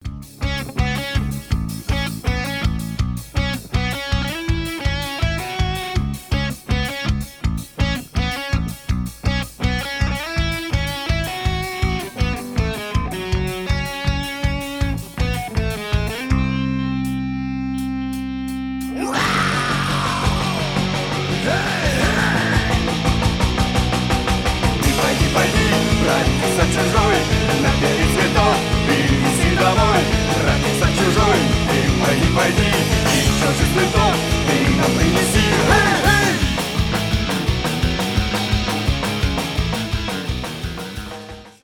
панк-рок
рок